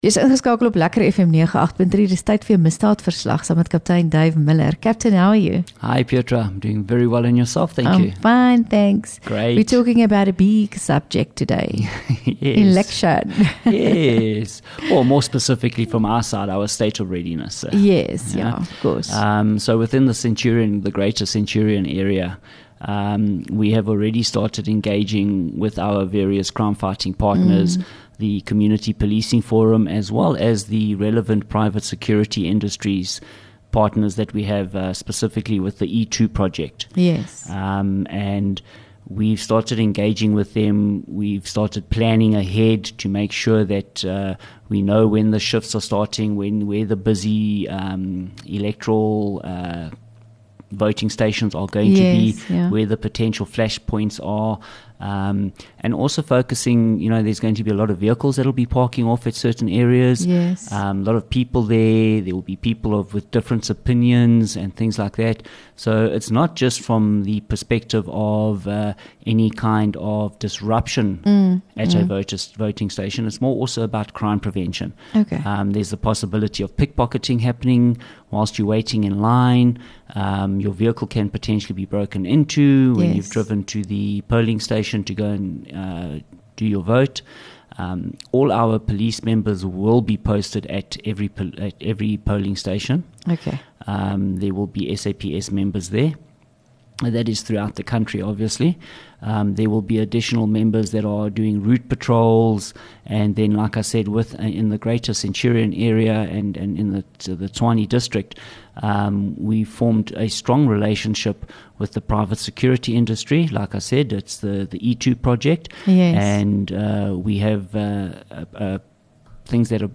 LEKKER FM | Onderhoude 21 May Misdaadverslag